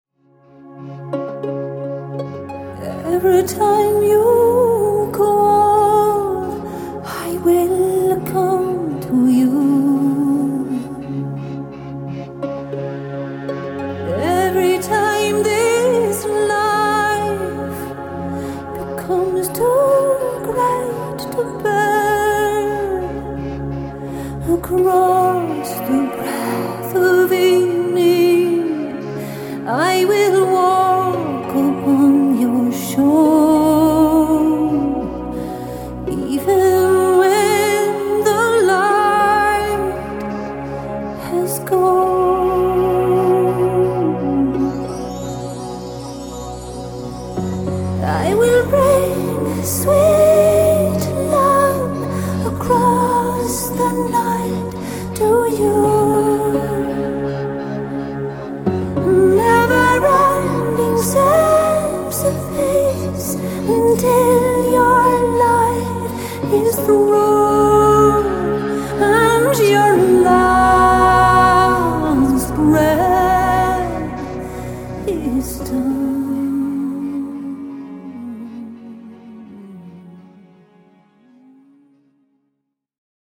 Performed Bass on
A beautiful ethereal ballad